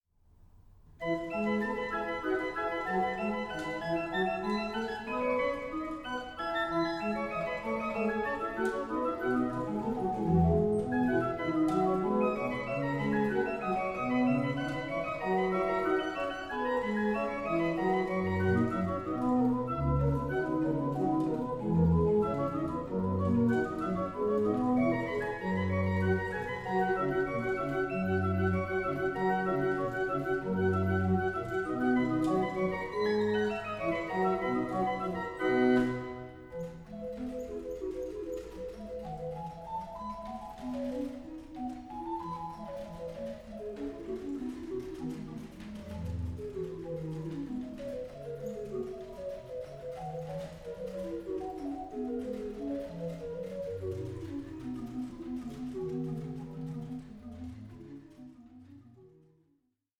Organ Concerto No.15 in D minor